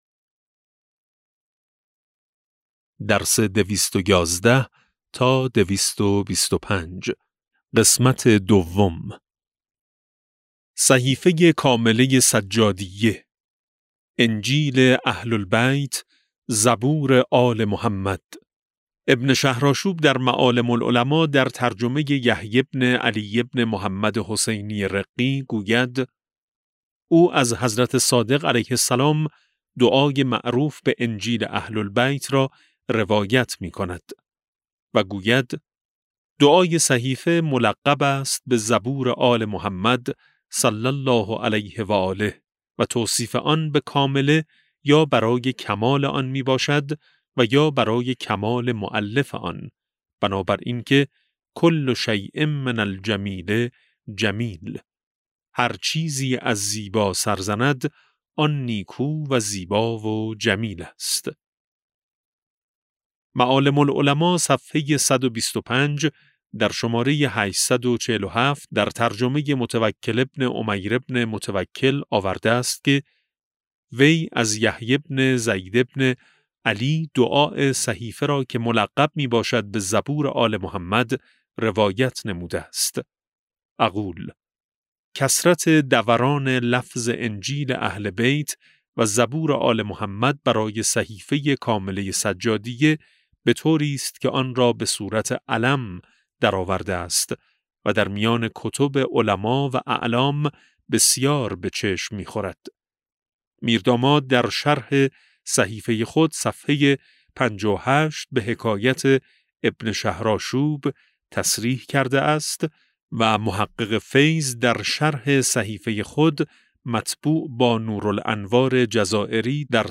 کتاب صوتی امام شناسی ج15 - جلسه2